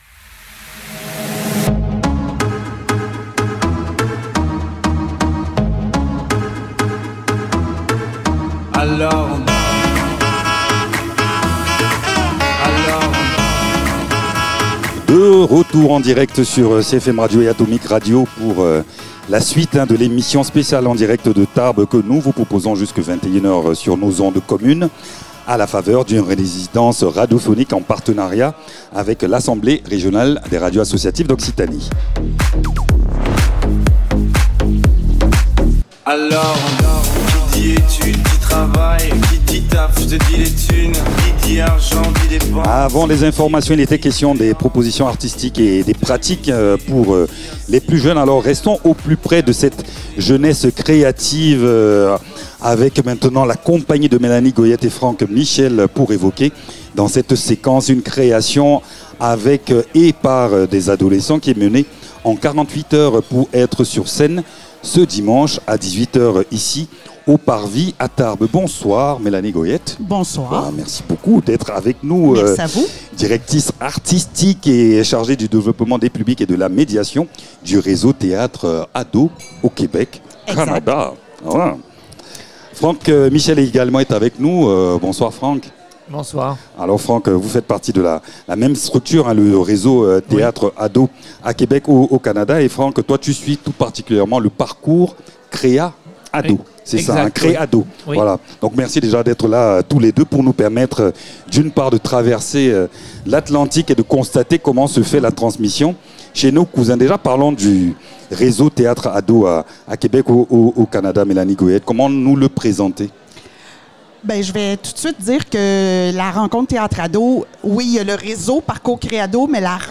Cette interview offre un aperçu de leur méthode, centrée sur la découverte, la créativité et la participation active des adolescents au processus artistique.